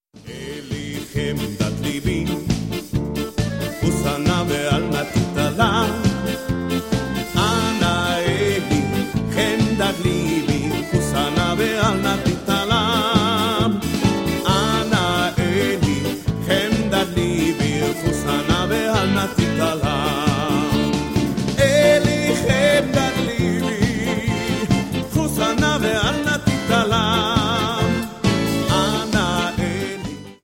• Sachgebiet: Klassik: Jüdische Musik